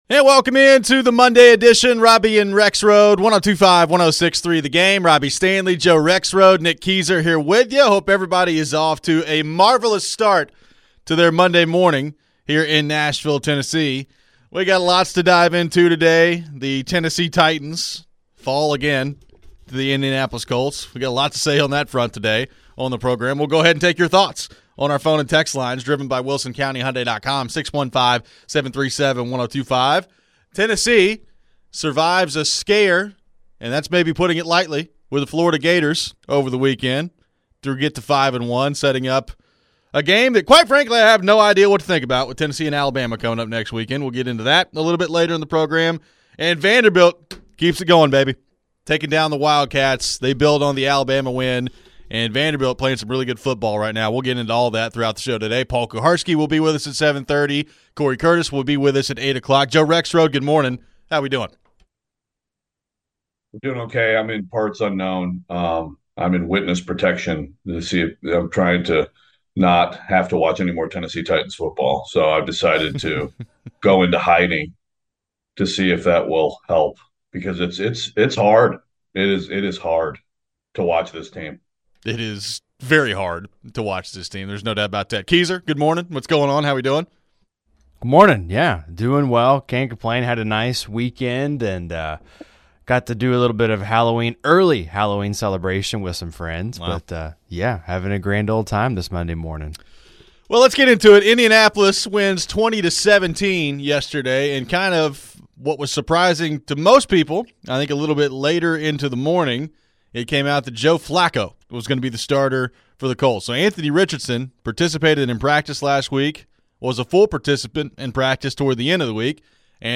Fans call in to share their thoughts on game as well.